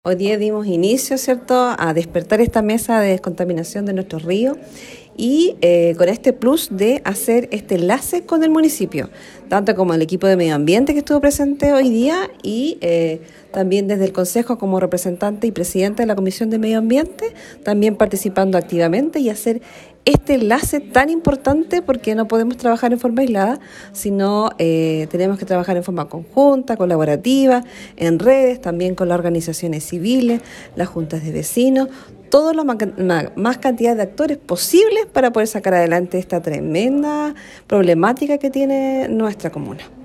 La Concejala de Osorno y Presidenta de la Comisión de Medioambiente, Cecilia Canales, señaló que crear este enlace con la mesa de trabajo por la descontaminación de los ríos es un paso muy importante para avanzar en un trabajo conjunto.